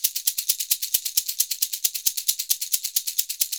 Index of /90_sSampleCDs/Univers Sons - Basicussions/11-SHAKER133